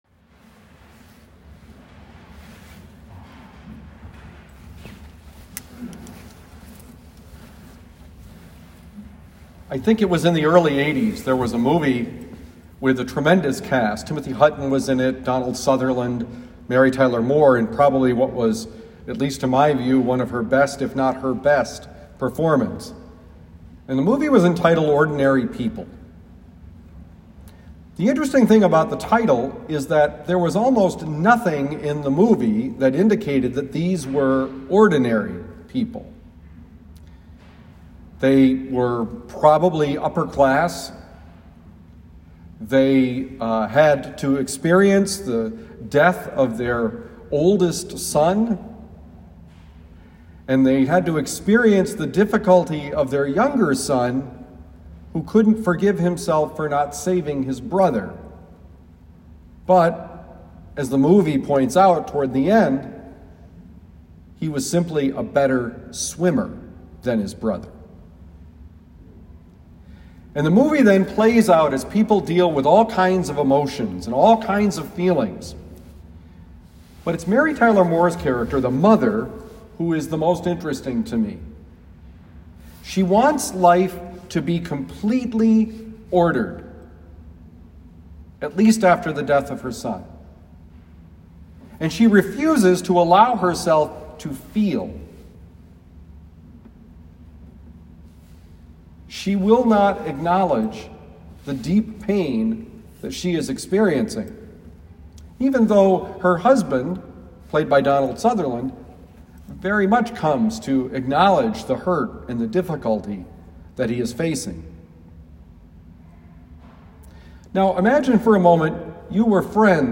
Homily for December 6, 2020
Given at Our Lady of Lourdes Parish, University City, Missouri.